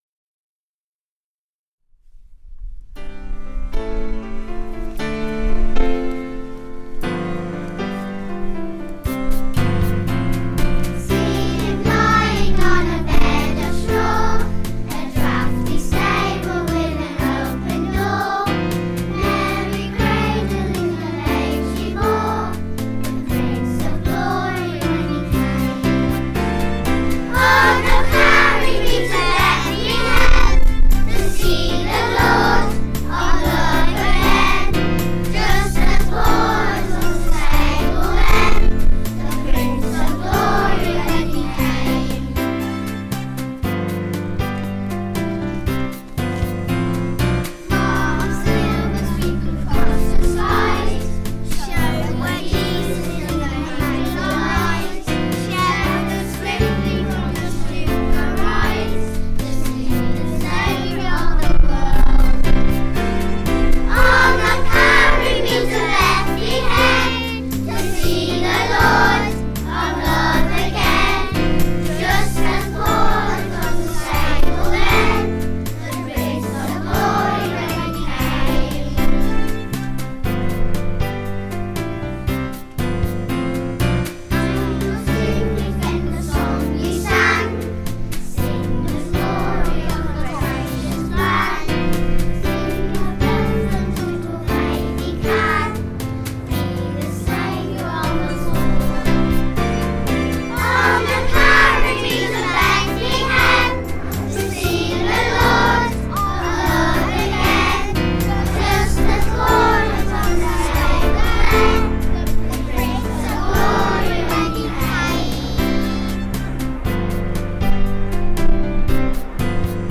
Year-2-Calypso-Carol.mp3